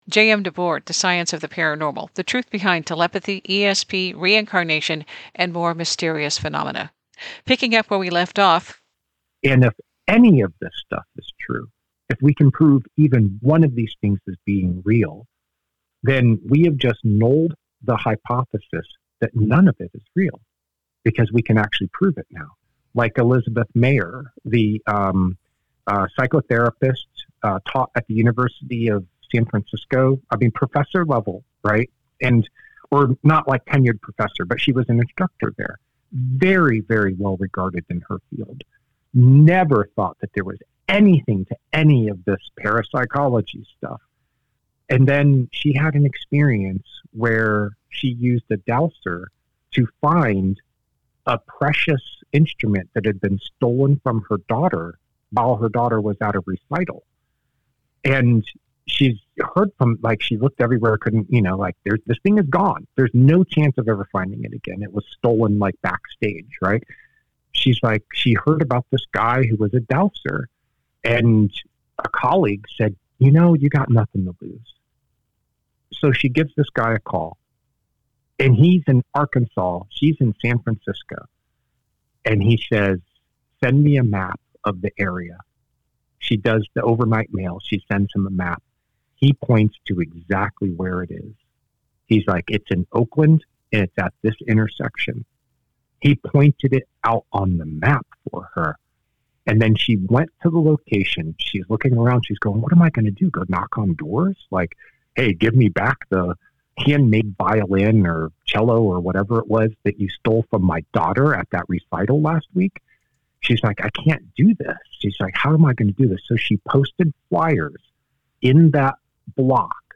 This interview highlights how dowsing solved the mystery of the stolen harp, and - not featured in the book - some of his own paranormal experiences.